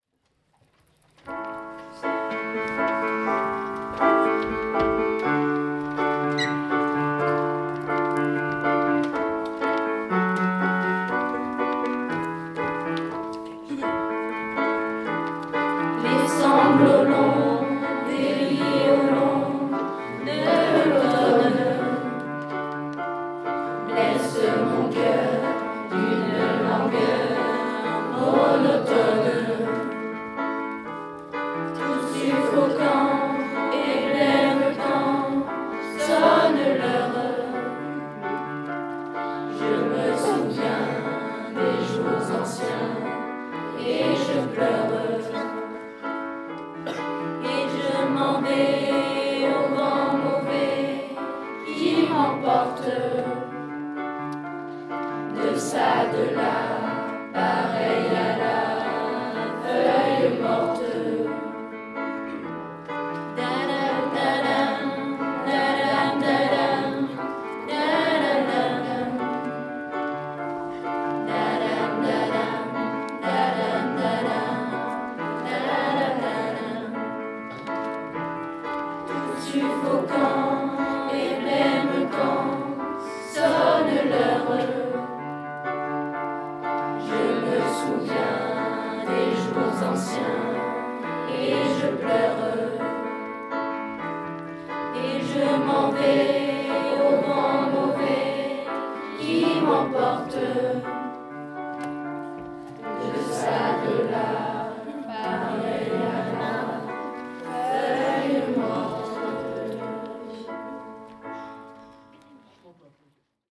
Les élèves du collège ont enregistré un chant pour les commémorations du 14 juillet 2024